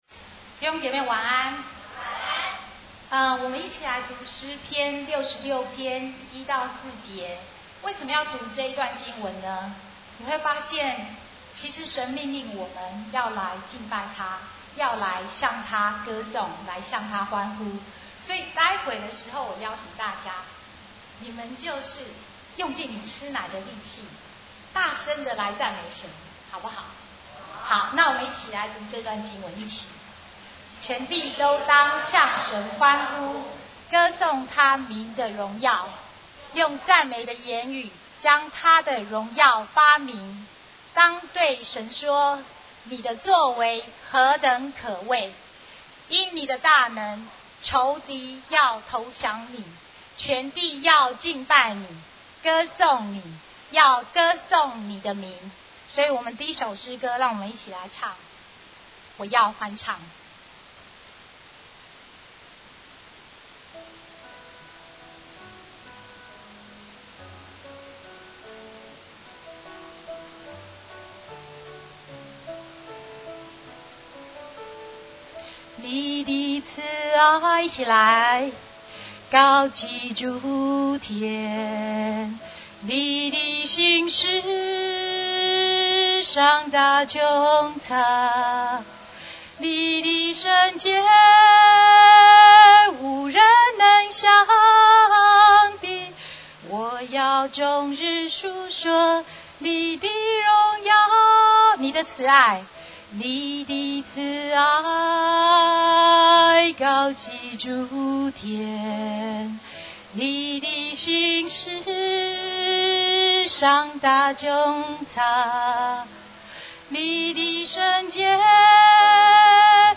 6月17日联合团契